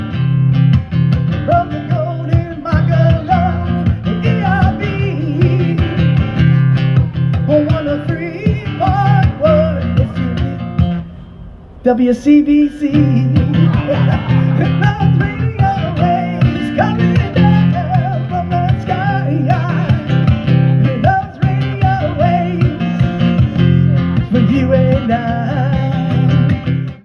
A wonderful sound once again in our community as Western Maryland steam engine 1309 returns to excursion service. The locomotive had been sidelined during 2024 season for work on its pistons and cylinders.